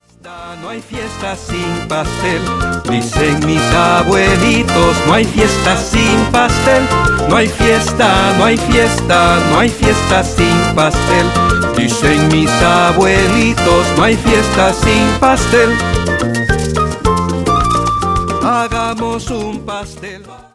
fabulosa fusión de estilos